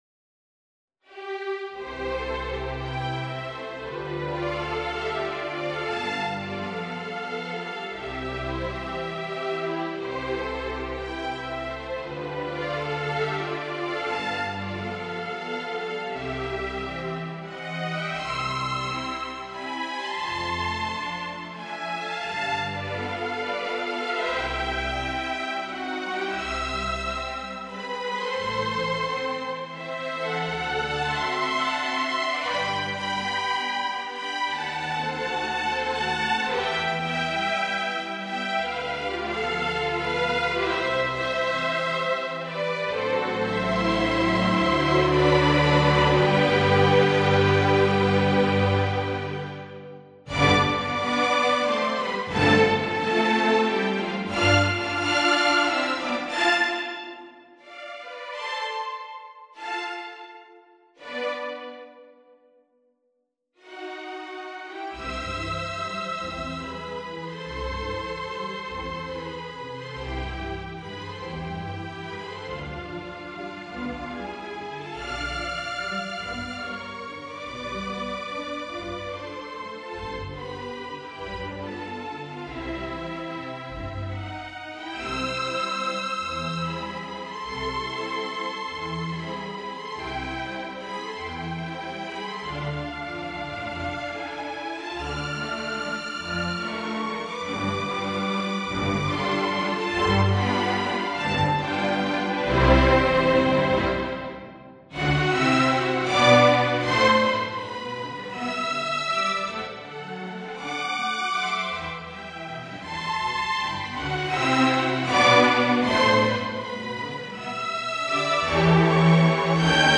"Wiener Blut" ("Viennese Blood") Waltzes (Johann Strauss, Jr.)
WienerBlut-orch.mp3